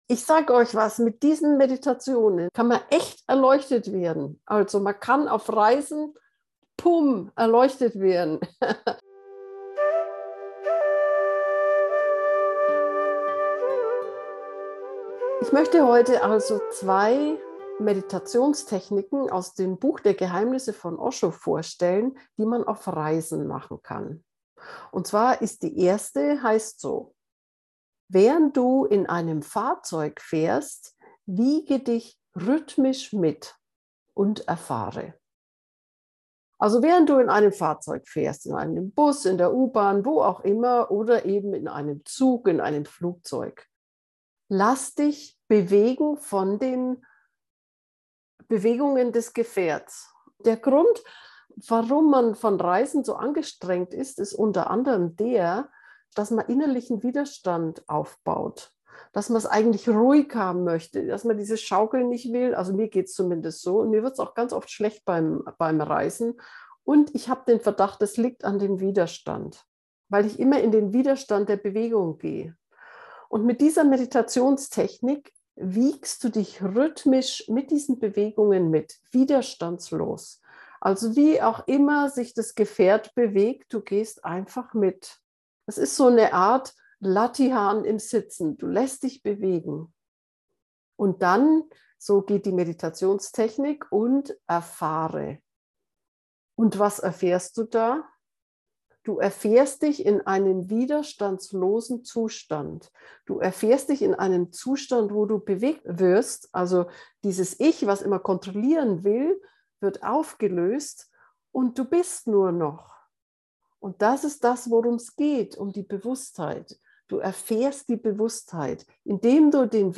meditation-reisen-meditation-gefuehrte-meditation